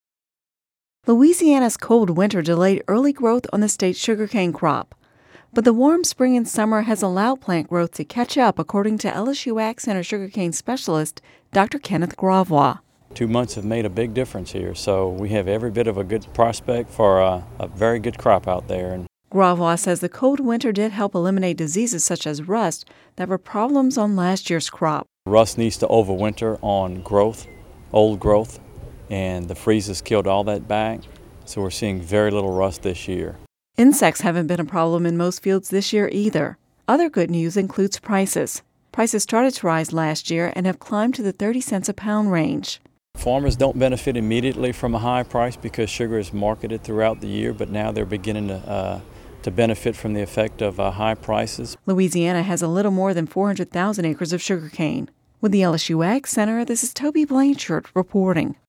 (Radio News 08/02/10)